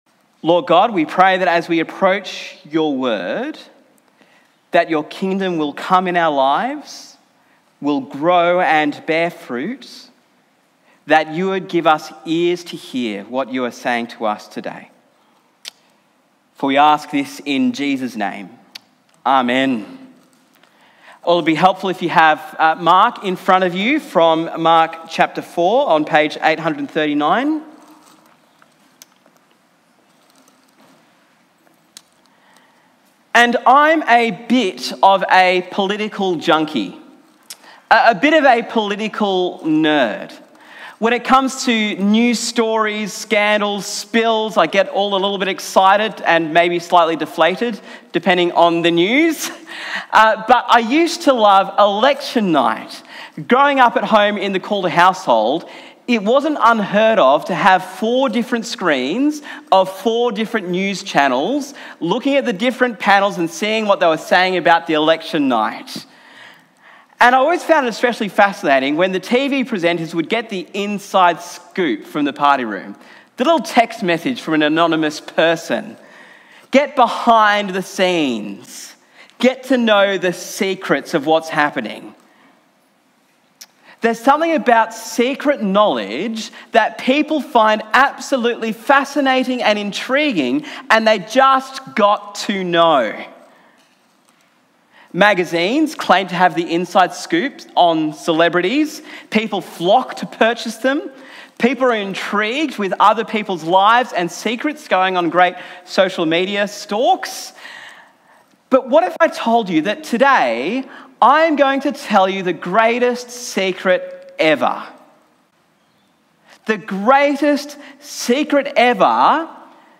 Sermon on Mark 4